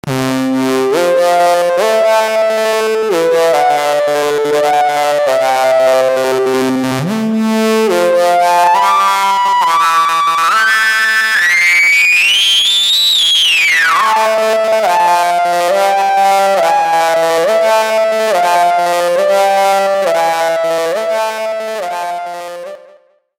All samples were recorded via overdub and enriched with effects under Logic.
a sync sound with an extreme envelope modulation rate - play it with low volume if you don't want to destroy your loudspeakers! ;-)